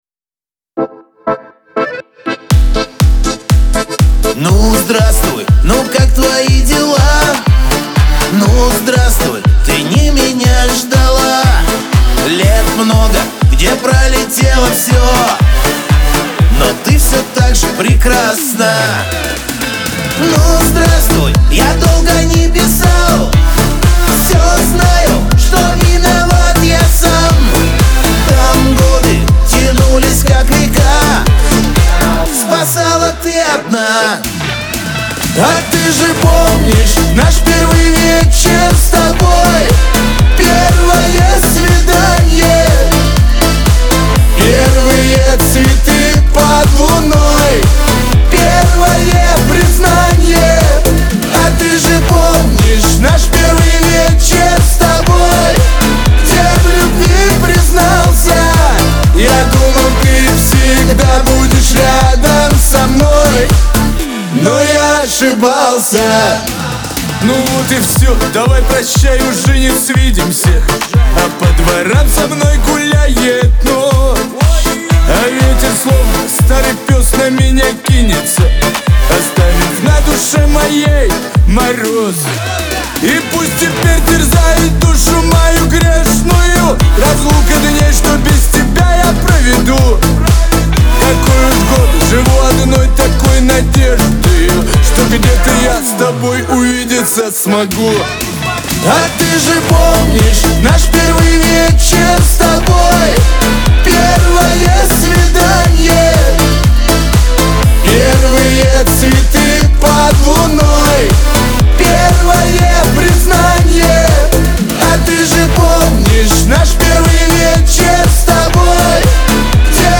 Лирика
дуэт
Шансон